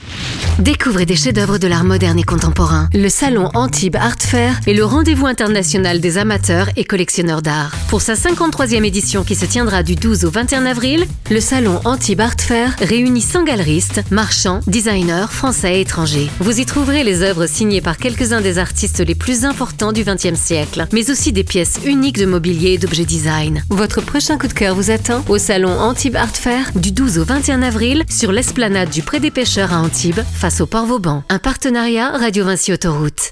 Notre spot radio en partenariat avec Radio Vinci Autoroute